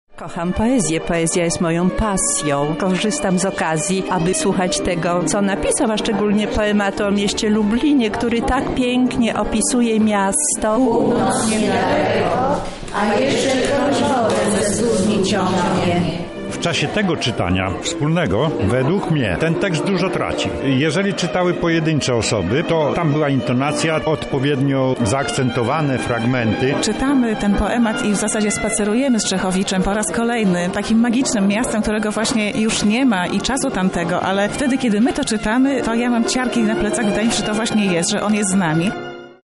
1 5 marca w 112 rocznicę urodzin, , drukarze, księgarze i bibliotekarze czytali utwory